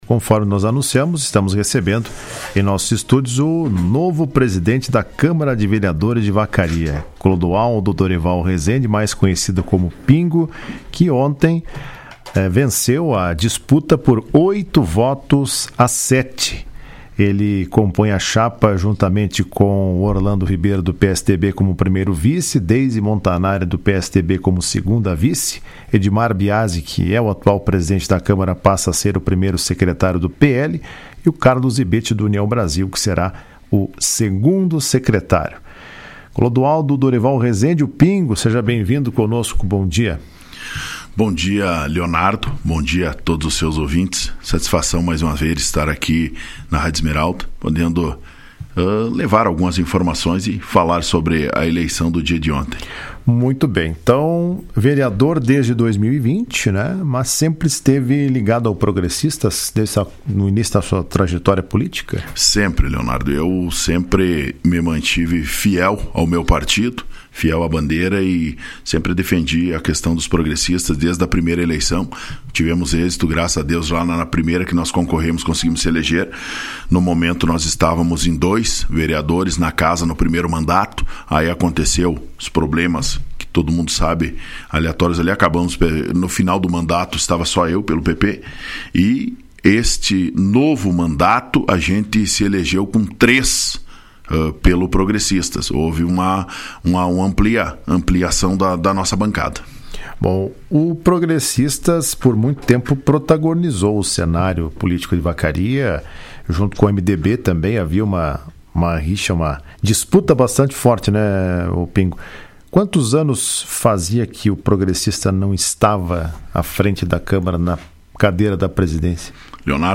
Resende participou do programa Comando Geral desta quarta-feira e falou das expectativas acerca do novo desafio que vai enfrentar a partir do ano que vem.